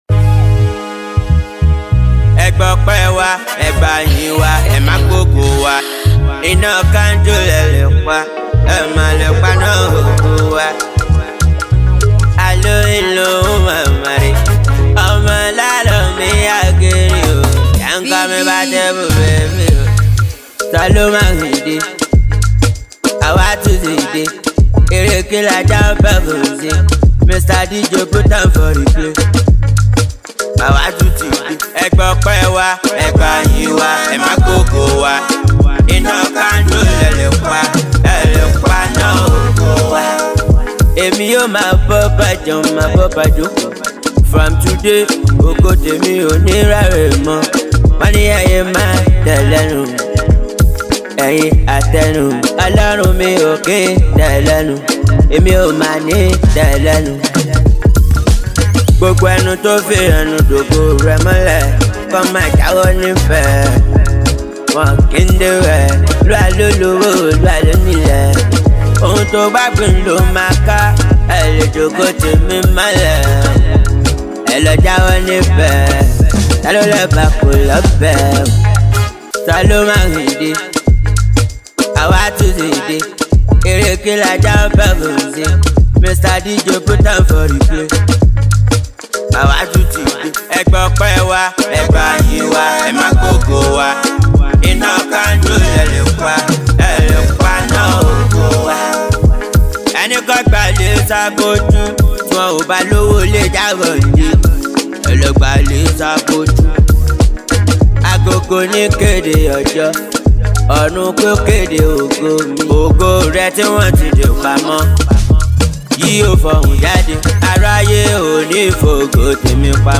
Street-pop sensation and talented songwriter
energetic new release
is a vibrant and catchy tune